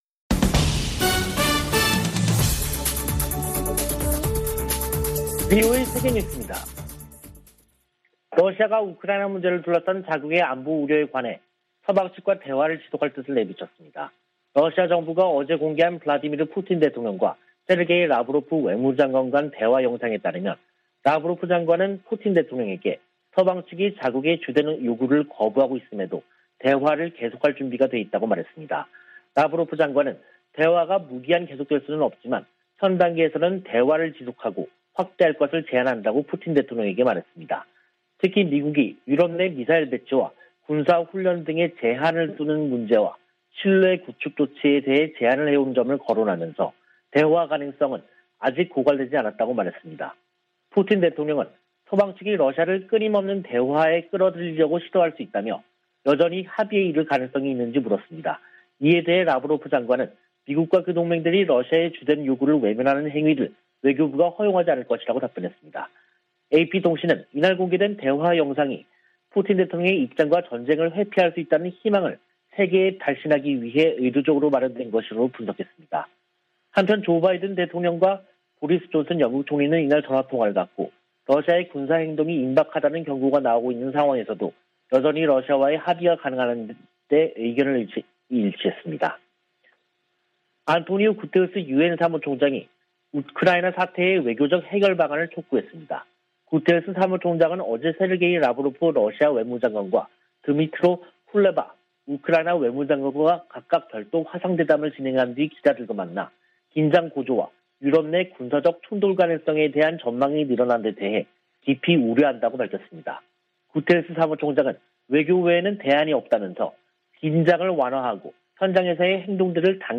VOA 한국어 간판 뉴스 프로그램 '뉴스 투데이', 2022년 2월 15일 3부 방송입니다. 미 국무부는 북한 영변 핵 시설이 가동 중이라는 보도에 대해 북한이 비확산 체제를 위협하고 있다고 비판했습니다. 조 바이든 미국 대통령이 물러날 때 쯤 북한이 65개의 핵무기를 보유할 수도 있다고 전문가가 지적했습니다. 미한일이 하와이에서 북한 문제를 논의한 것과 관련해 미국의 전문가들은 3국 공조 의지가 확인됐으나, 구체적인 대응이 나오지 않았다고 평가했습니다.